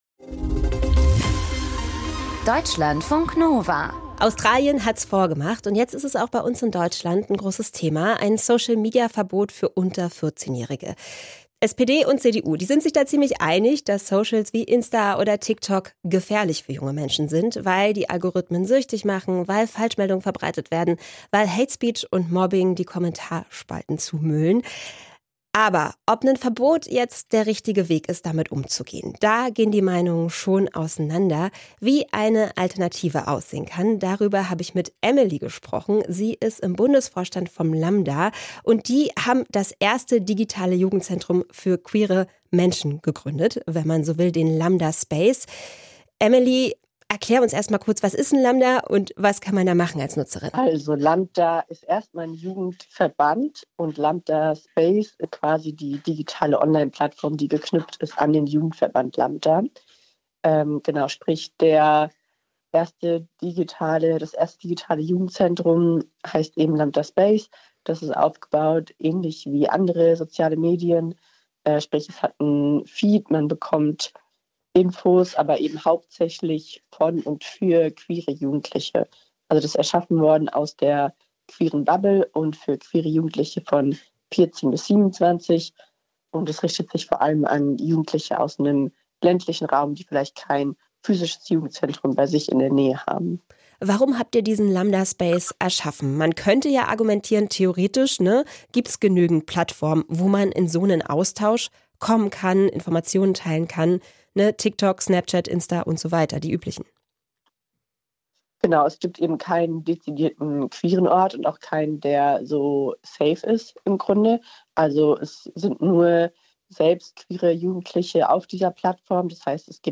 spricht bei Deutschlandfunk Nova über das Social Media Verbot und lambda space.